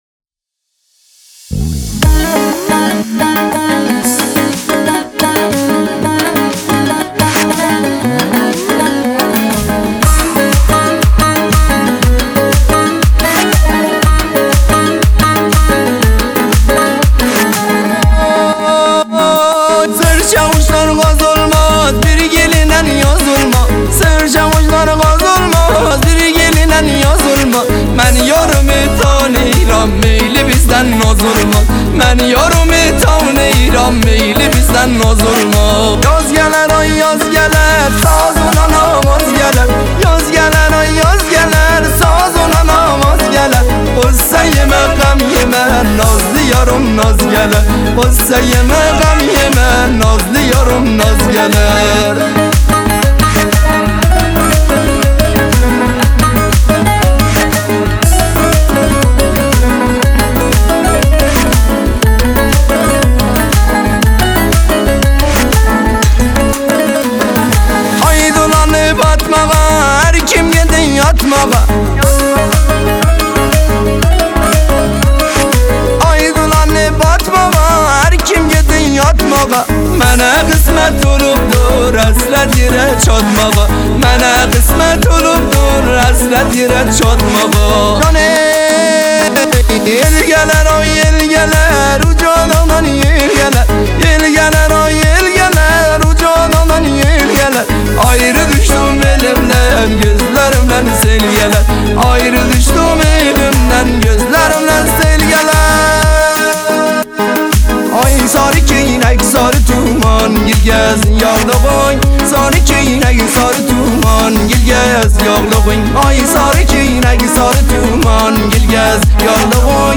موسیقی ترکی قشقایی